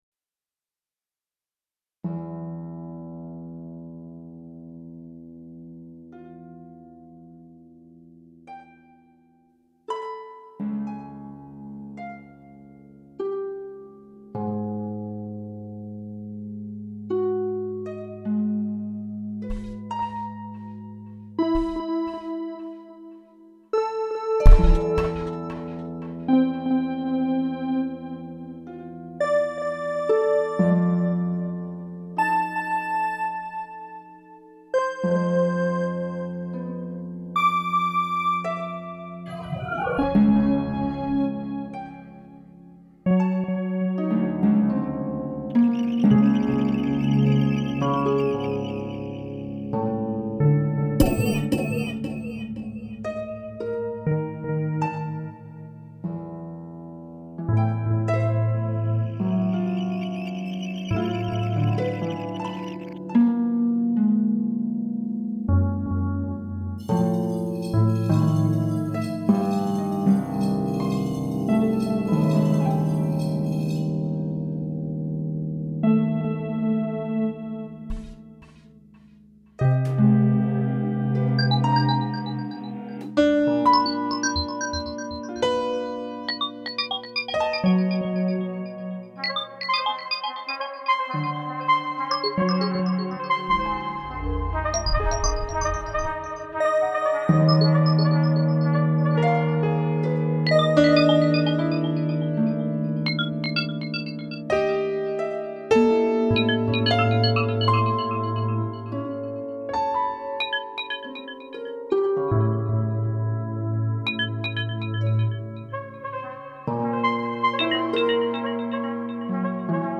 Plucked piano, Synth- Strings
Electro Acoustics
Synth- Trumpet